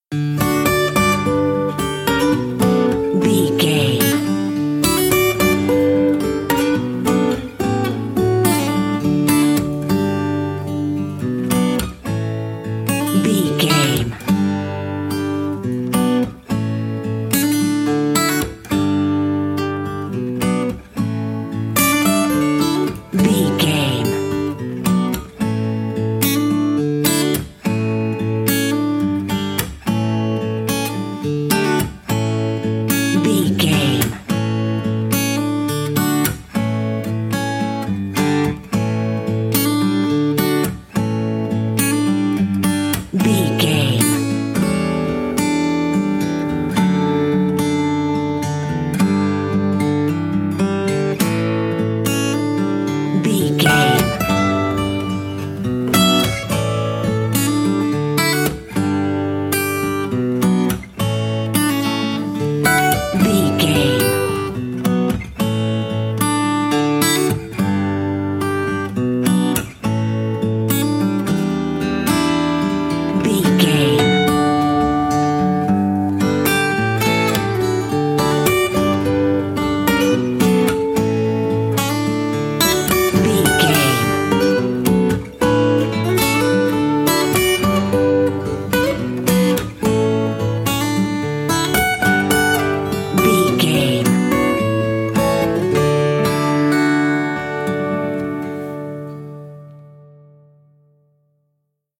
Ionian/Major
DOES THIS CLIP CONTAINS LYRICS OR HUMAN VOICE?
acoustic guitar